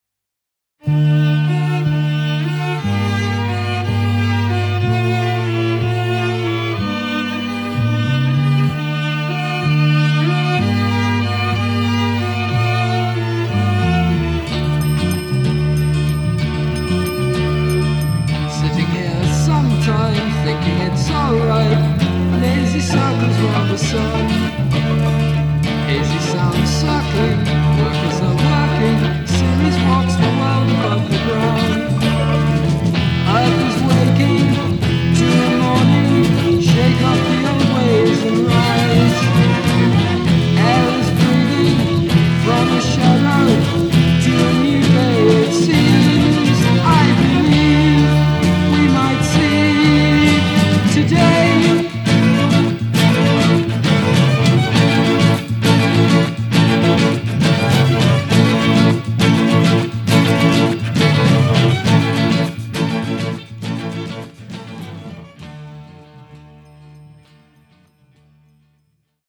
guitars, lead vocals
drums, percussion
Recorded in Torquay, 1972